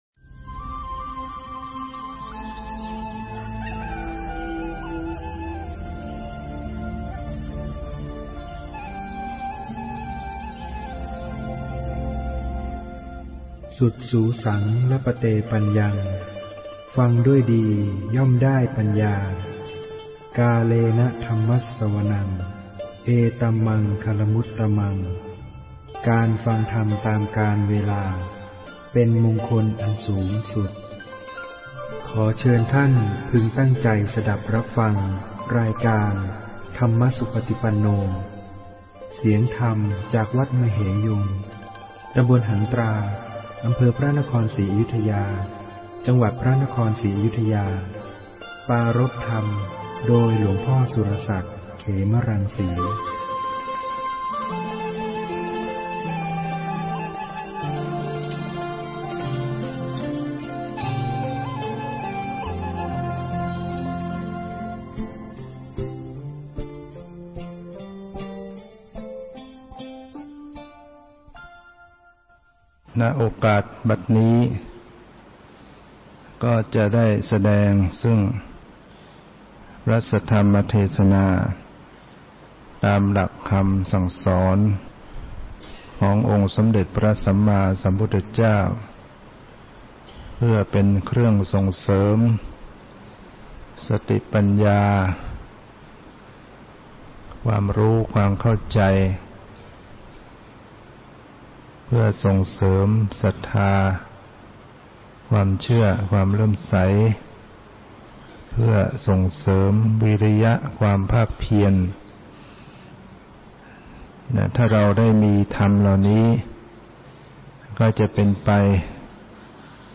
ฟังธรรมะ Podcasts